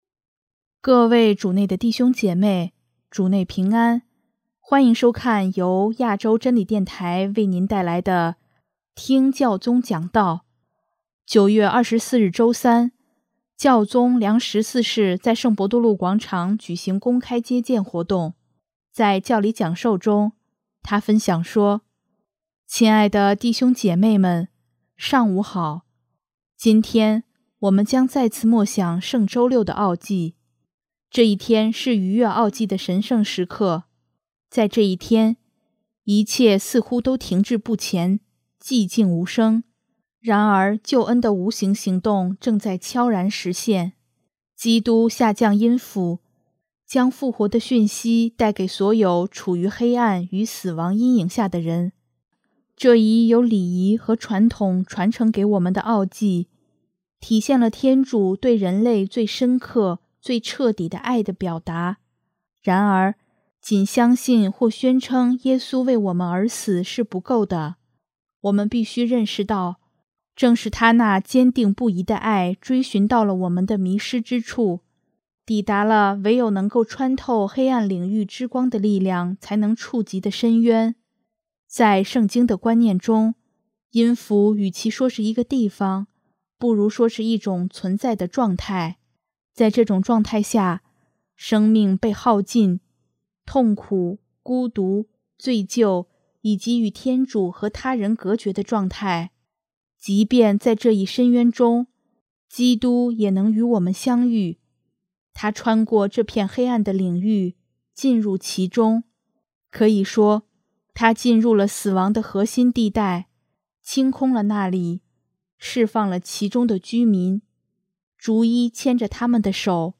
9月24日周三，教宗良十四世在圣伯多禄广场举行公开接见活动。